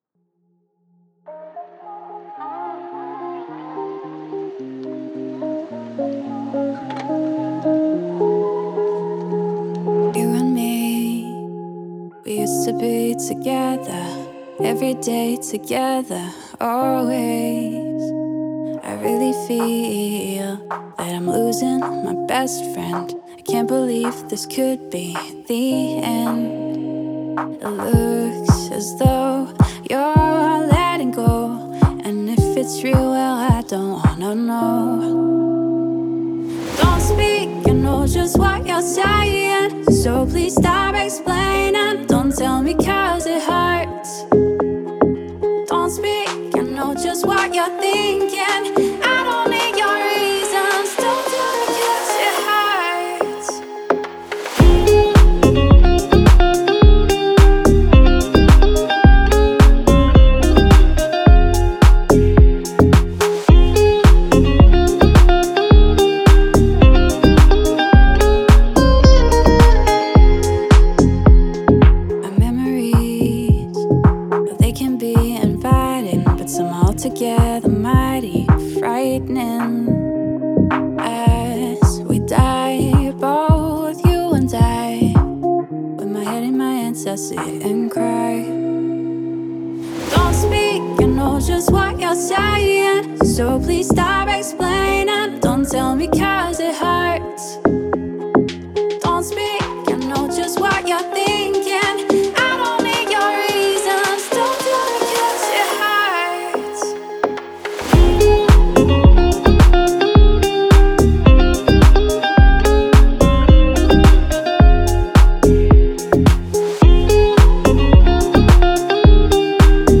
это трек в жанре поп с элементами электронной музыки